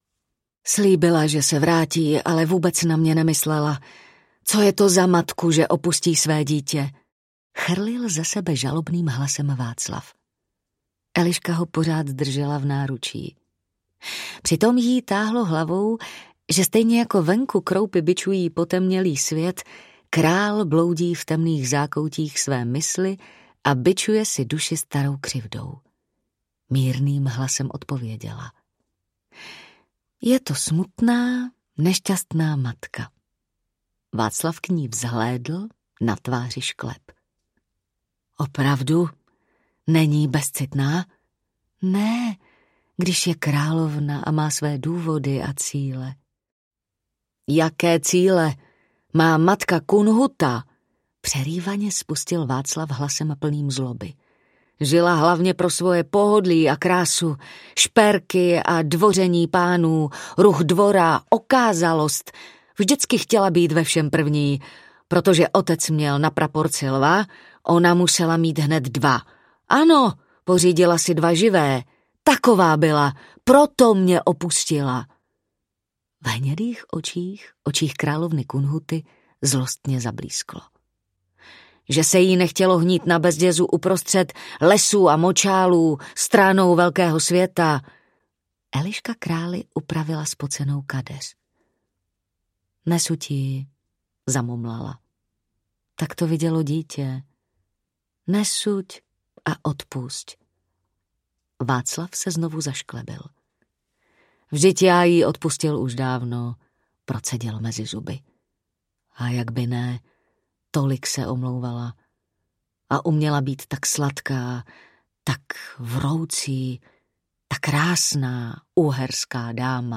Markéta Přemyslovna audiokniha
Ukázka z knihy
Vyrobilo studio Soundguru.